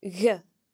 If dh is used before a broad vowel (a, o, or u), it is pronounced similarly to the English ‘g’, as can be heard in dhà (the dependent form of , two):